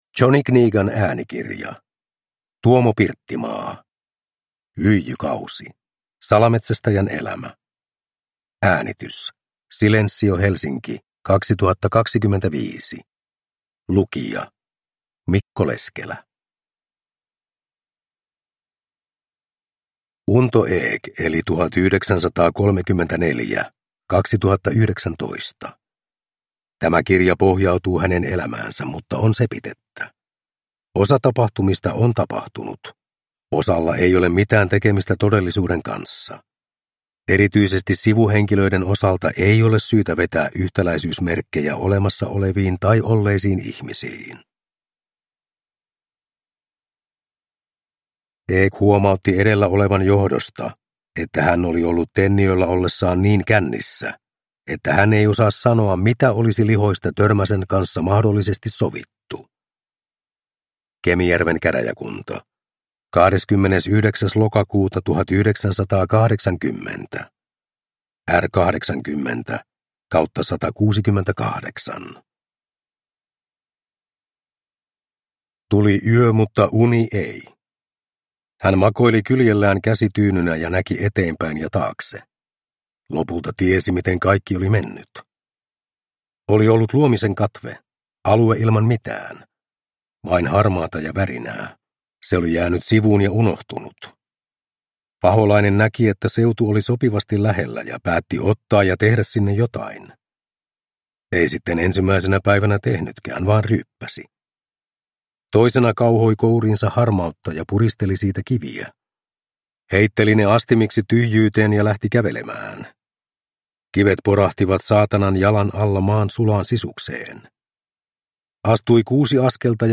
Lyijykausi (ljudbok) av Tuomo Pirttimaa